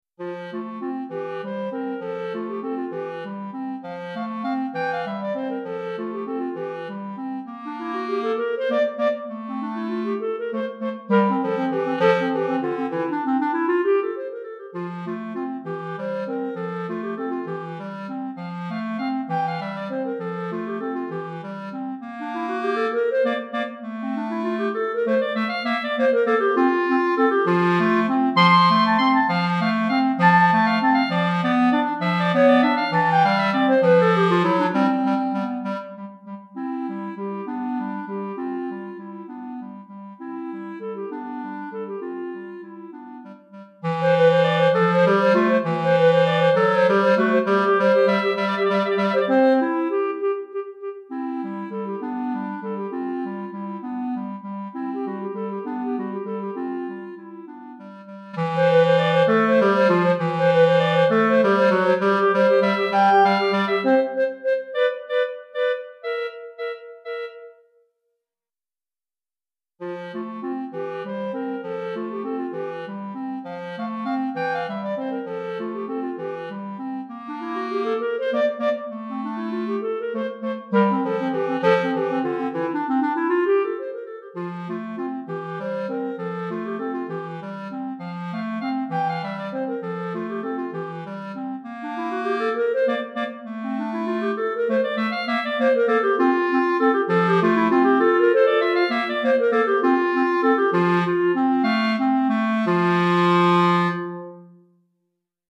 2 Clarinettes